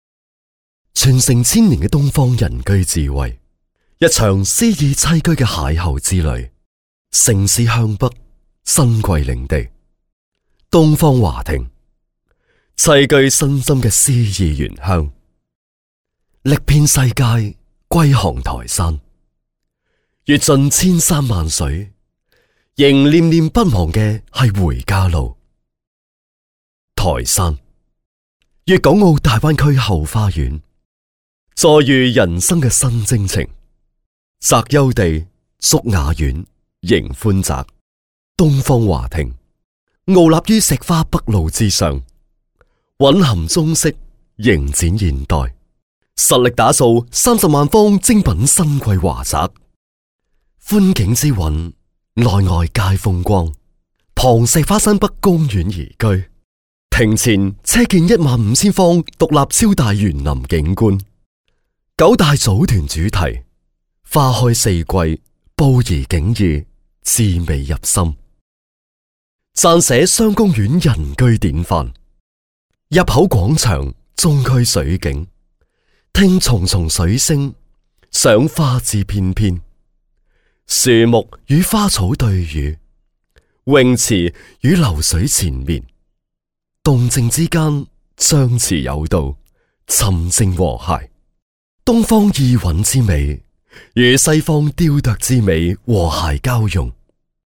10 男粤3_广告_地产_东方华庭 男粤3
地产广告配音
男粤3_广告_地产_东方华庭.mp3